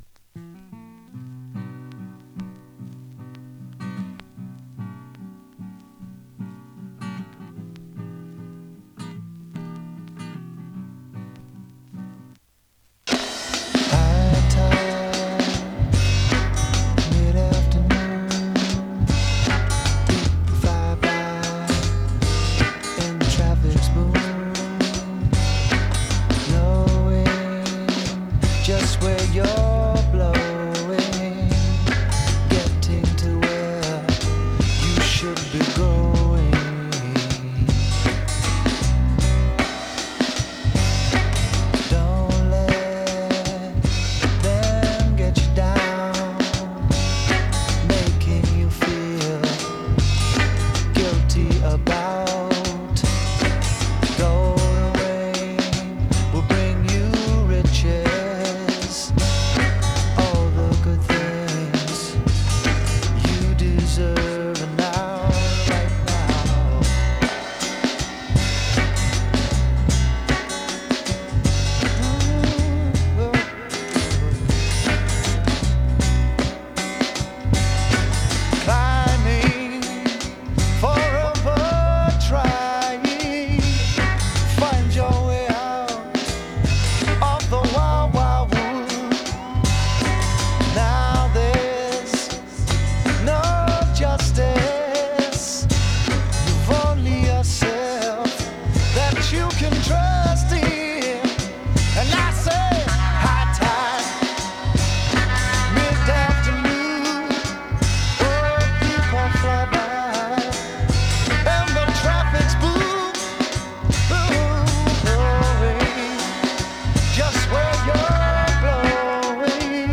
[3track 7inch]＊音の薄い部分で時おり軽いチリパチ・ノイズ。